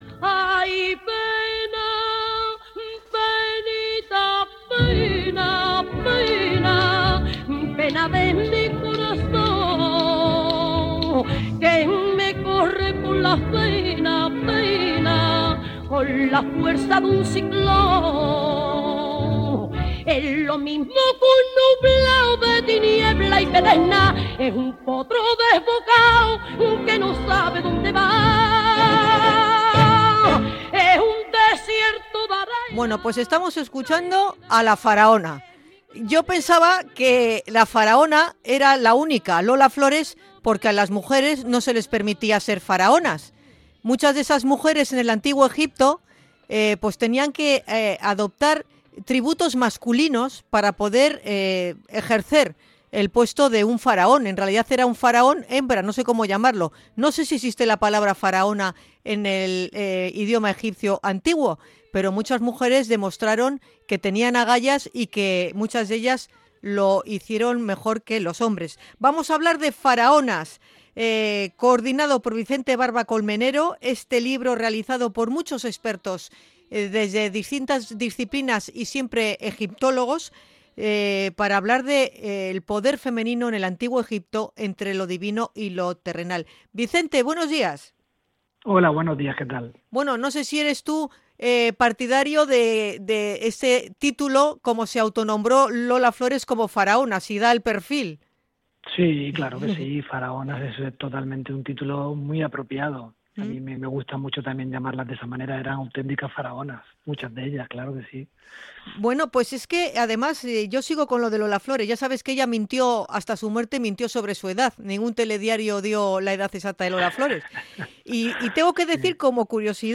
Entrevista a autor de libro sobre las faraonas egipcias